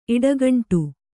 ♪ iḍagaṇṭu